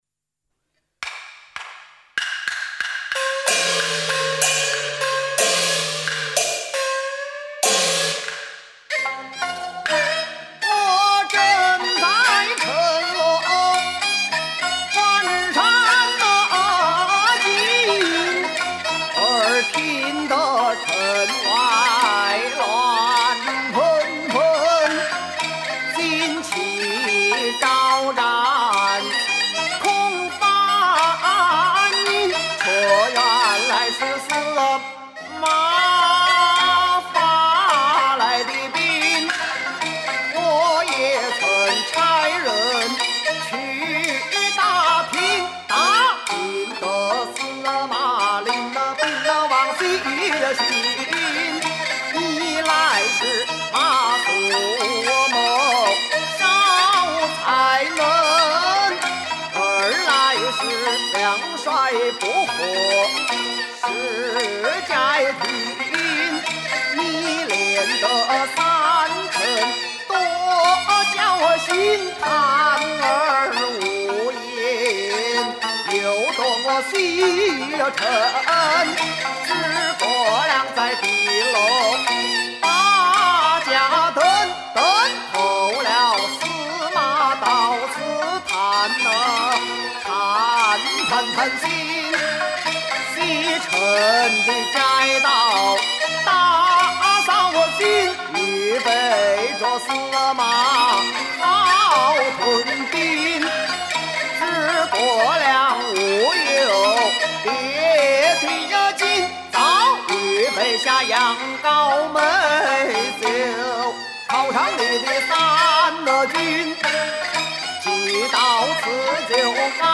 京剧老生演员
［西皮二六］